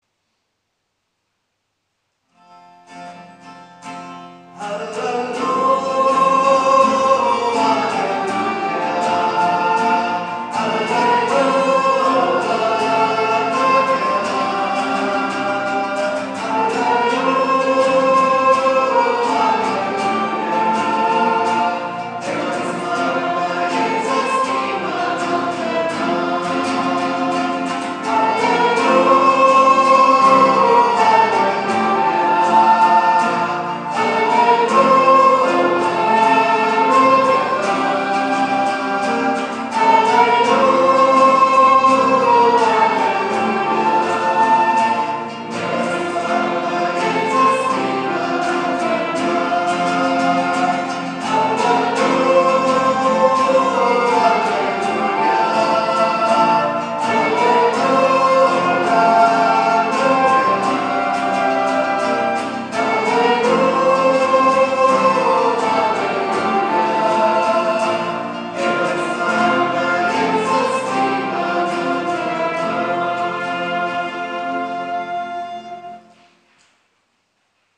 Pregària de Taizé
Parròquia de la M.D. de Montserrat - Diumenge 23 de febrer de 2014